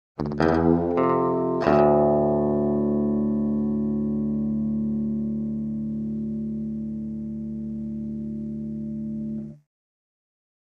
Electric Guitar Twang - Texas Melody 5, (Slide Guitar) - Clean Guitar